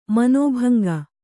♪ manōbhanga